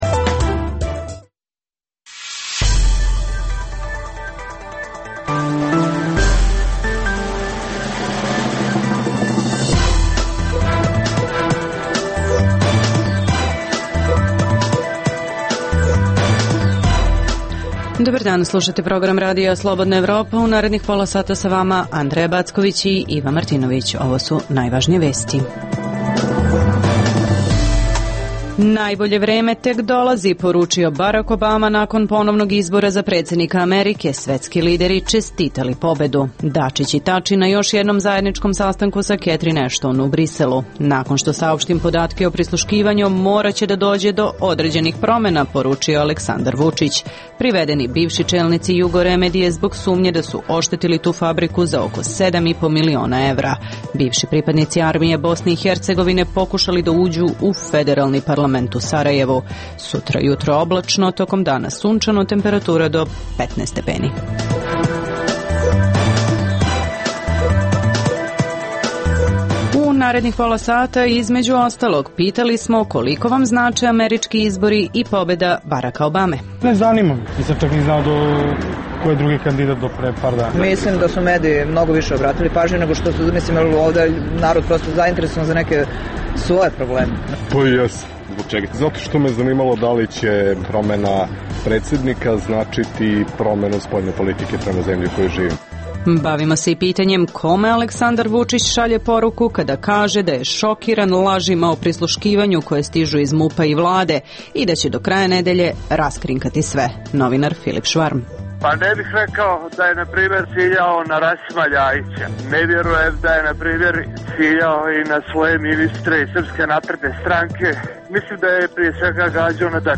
Poslušajte pobednički govor reizabranog predsednika, čestitku koju mu je uputio protivkandidat Mit Romni, atmosferu sa njujorškog Times Squera, te prve analize. Donosimo i rekacije iz Srbije.